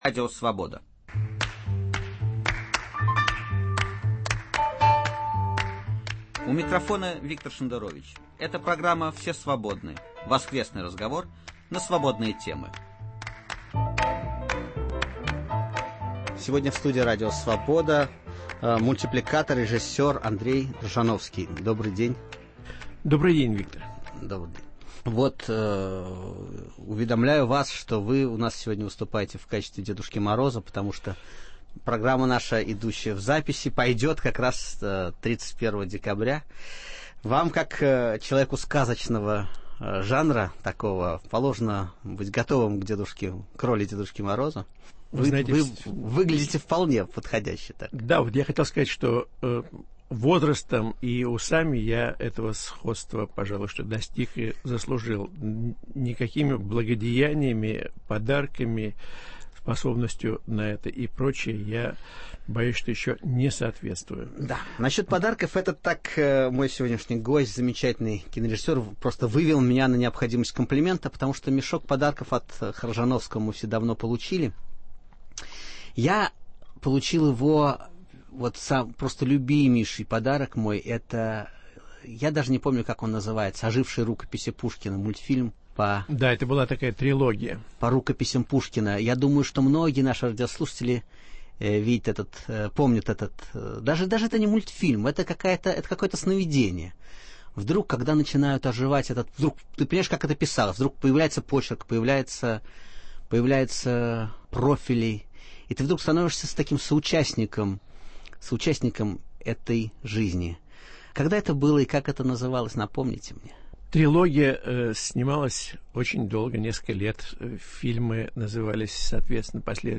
В гостях у Виктора Шендеровича - мультипликатор, режиссер Андрей Хржановский.